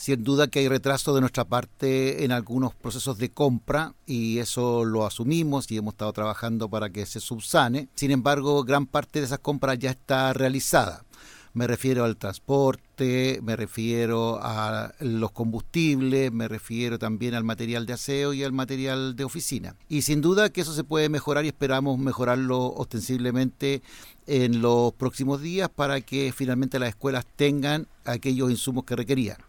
Entrevista a director ejecutivo de SLEP Valdivia
Quien, en entrevista con La Radio, abordó los cuestionamientos de las comunidades educativas sobre la falta de insumos básicos, reconociendo retrasos.